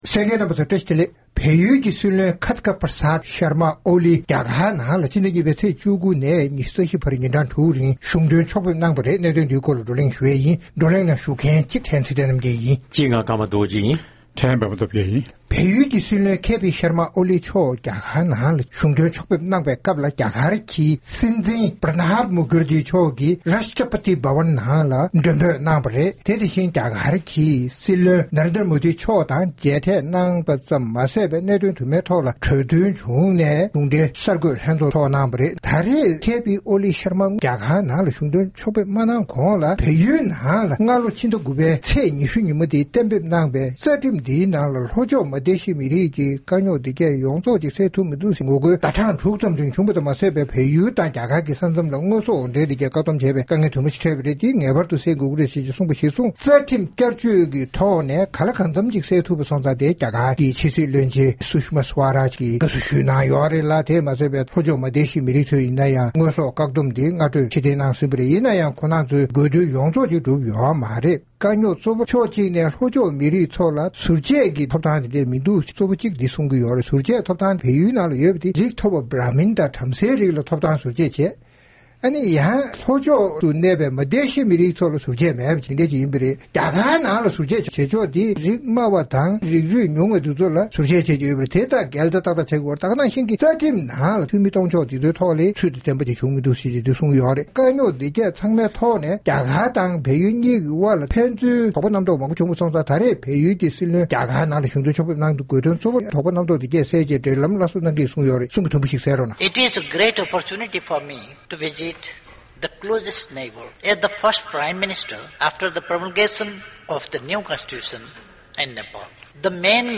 ༄༅། །བལ་ཡུལ་གྱི་སྲིད་བློན་ཕྱི་ཟླ་༢ཚེས་༡༩ནས་༢༤བར་ཉིན་གྲངས་དྲུག་རིང་རྒྱ་གར་ནང་ཕྱོགས་ཕེབས་གནང་ཡོད་པ་རེད། དེའི་སྐོར་ང་ཚོའི་རྩོམ་སྒྲིག་འགན་འཛིན་རྣམ་པའི་དབར་བགྲོ་གླེང་གནང་བ་དེ་གསན་རོགས་གནང་།